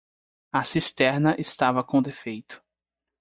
Pronounced as (IPA)
/sisˈtɛʁ.nɐ/